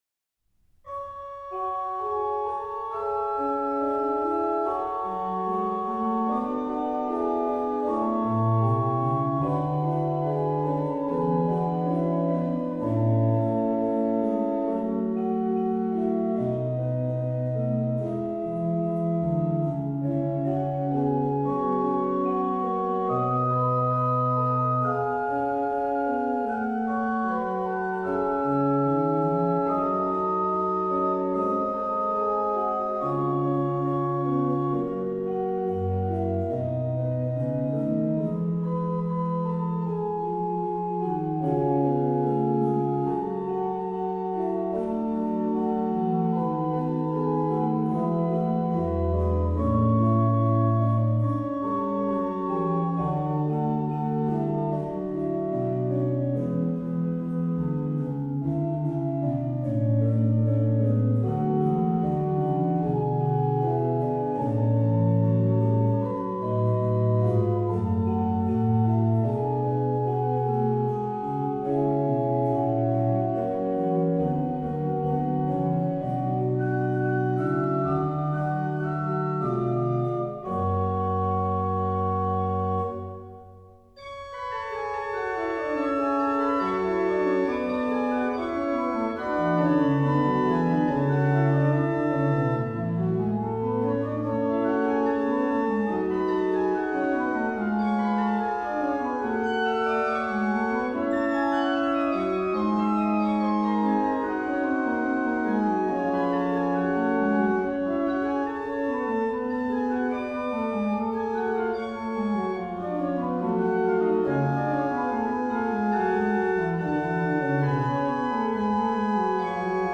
Registration   OW: Ged8, Rfl4
Ped: Pr16, Oct8